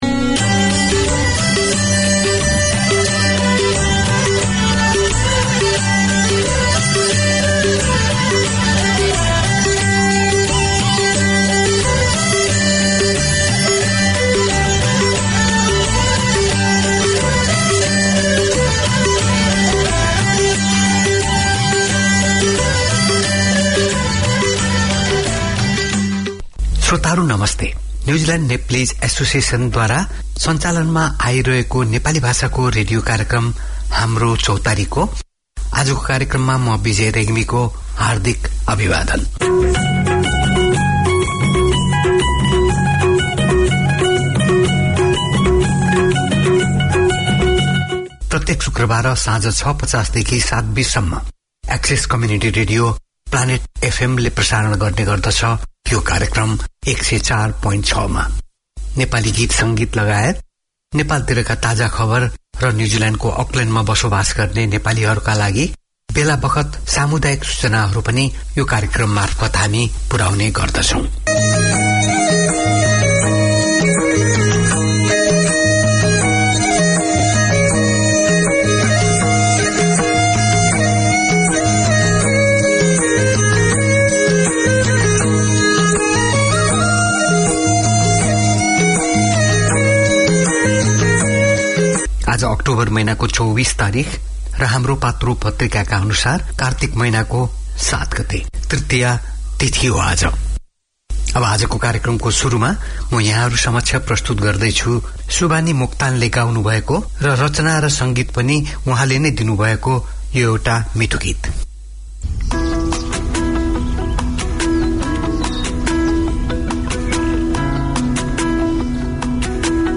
Each week Haamro Chautari offers the chance for the Nepalese community to gather round and share their culture, news of the local community as well as the latest from Nepal. The hosts also present a selection of new music and golden hits. Interviews and updates on community events in Auckland keep the connection with the Nepalese way of life.